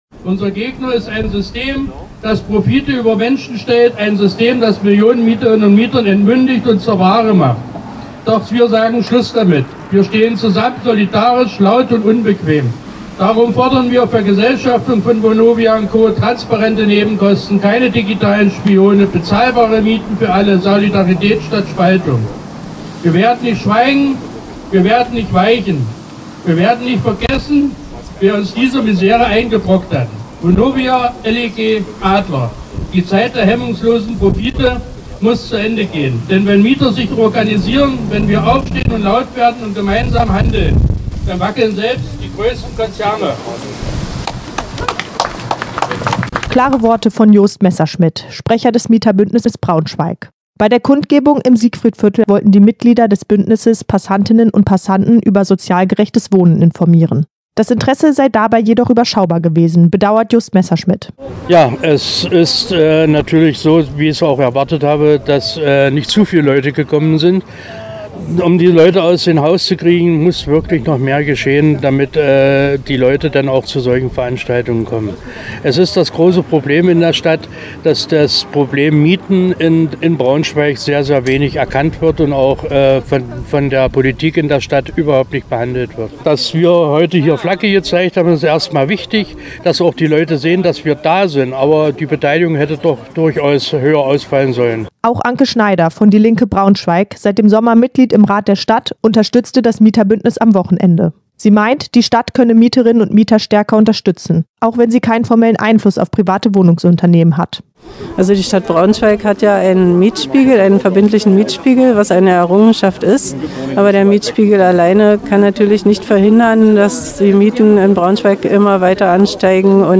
BmE-Kundgebung-Mieterbuendnis.mp3